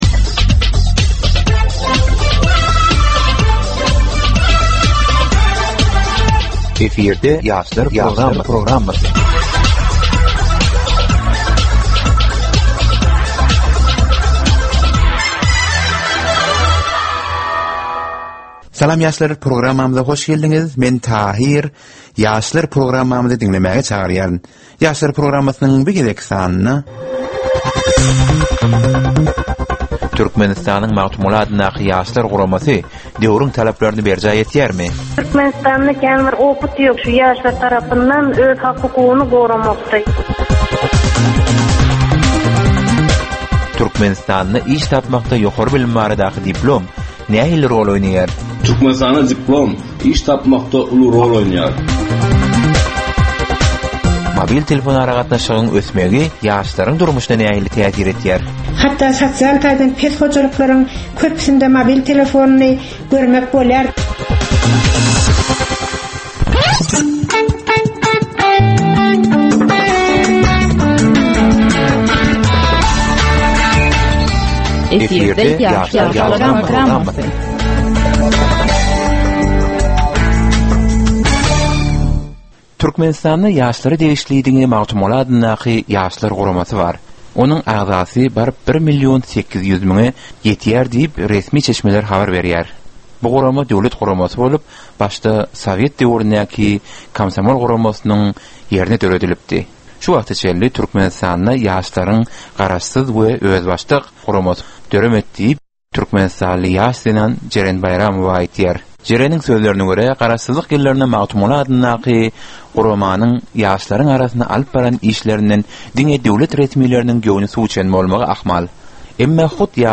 Türkmen we halkara yaslarynyn durmusyna degisli derwaýys meselelere we täzeliklere bagyslanylyp taýýarlanylýan 15 minutlyk ýörite geplesik. Bu geplesiklde ýaslaryn durmusyna degisli dürli täzelikler we derwaýys meseleler barada maglumatlar, synlar, bu meseleler boýunça adaty ýaslaryn, synçylaryn we bilermenlerin pikrileri, teklipleri we diskussiýalary berilýär. Geplesigin dowmynda aýdym-sazlar hem esitdirilýär.